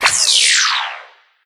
Imperial Stun Setting Botão de Som
Sound Effects Soundboard1 views